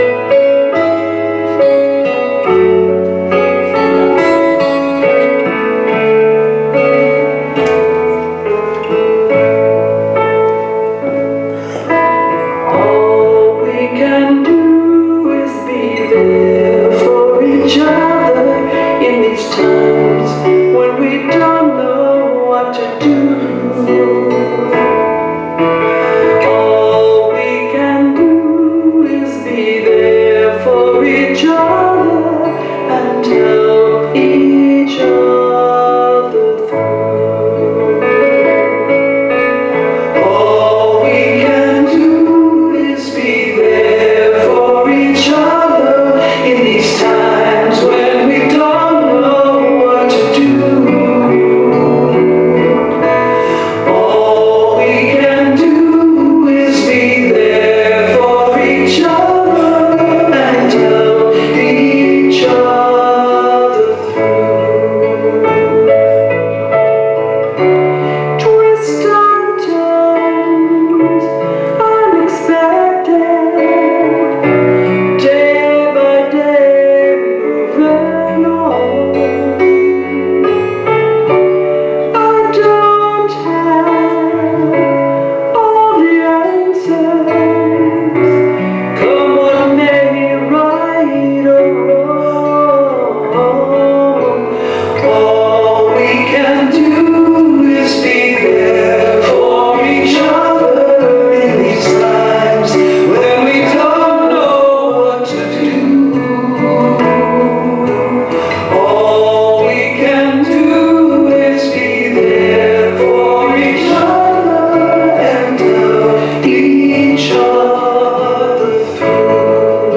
Series: Sermons 2023